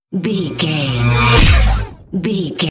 Sci fi pass by shot
Sound Effects
futuristic
pass by
vehicle